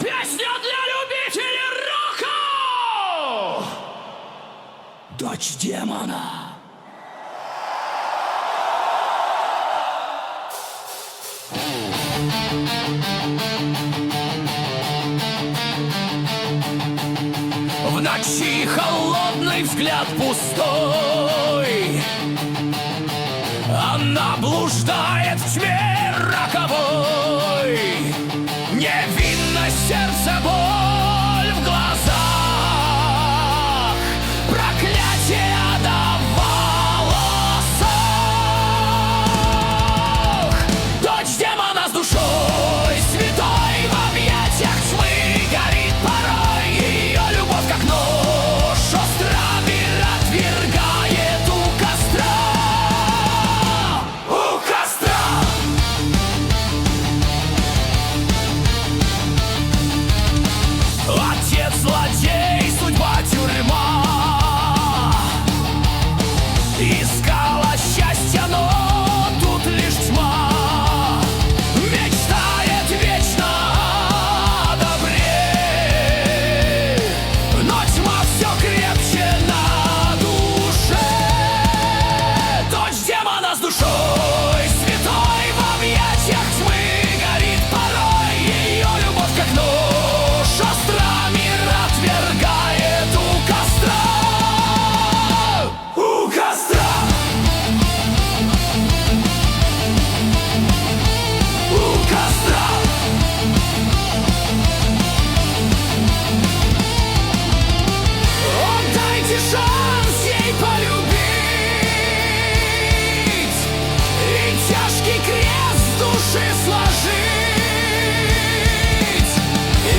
Песня для любителей рока